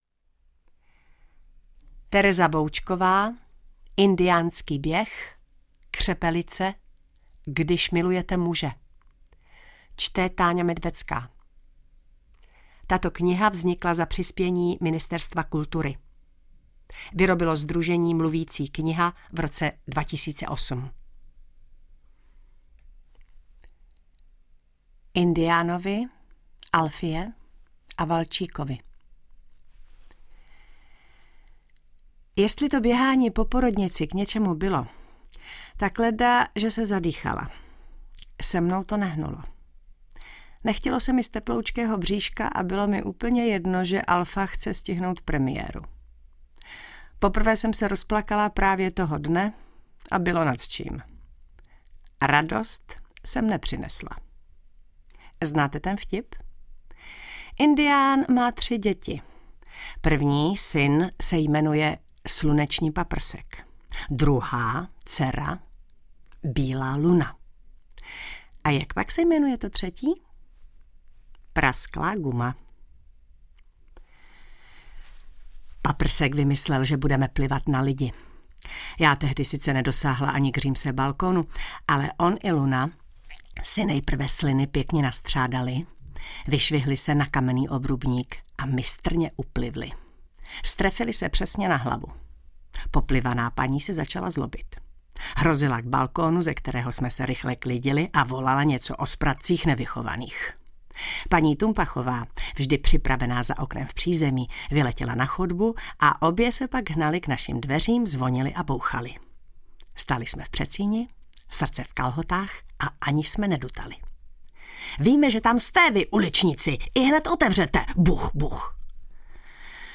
Čte: Táňa Medvecká